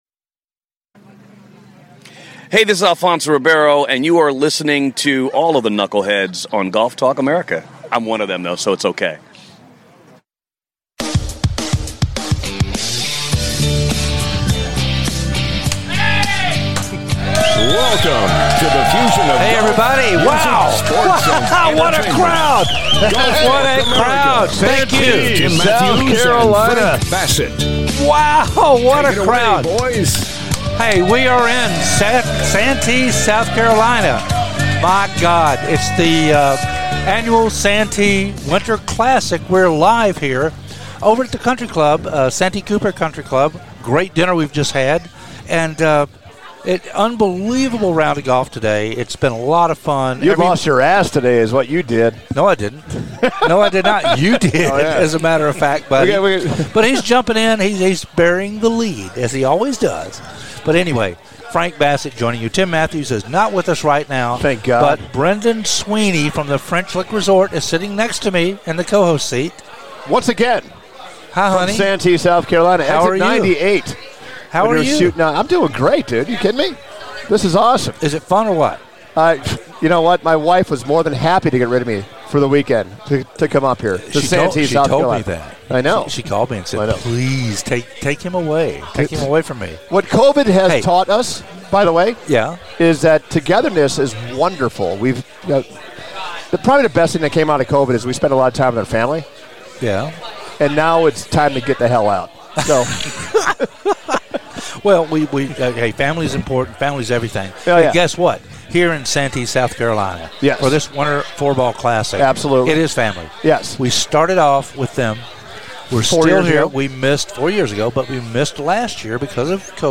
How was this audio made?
"LIVE" FROM THE SANTEE WINTER CLASSIC "OMG"